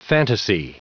Prononciation du mot fantasy en anglais (fichier audio)
Prononciation du mot : fantasy